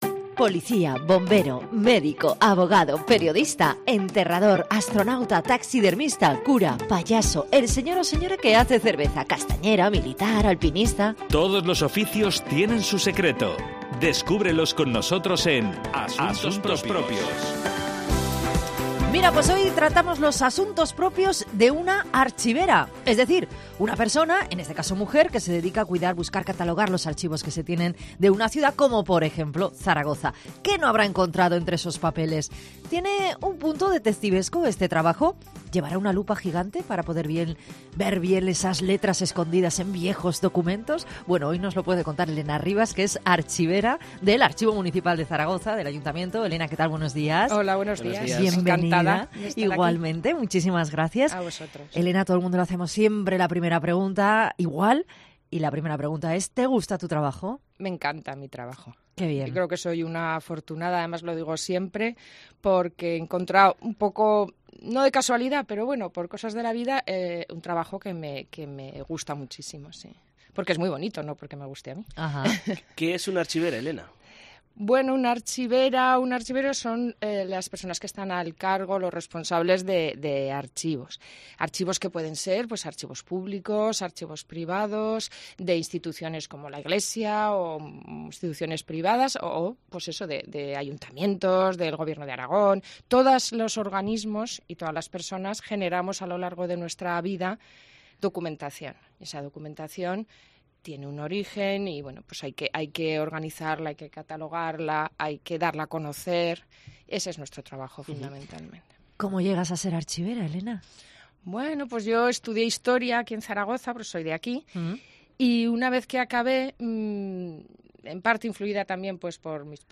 Nos habla también de todo lo que hay sobre Los Sitios de Zaragoza, o de cómo era la ciudad antes con sus puertas y los campos de labranza. 'Asuntos Propios' es una sección del programa 'Mediodía en COPE MAS Zaragoza' que se emite todos los jueves sobre las 13,30 horas. En esta sección tratamos de conocer el día a día de diferentes profesiones a traves del testimonio de quienes trabajan en ellas.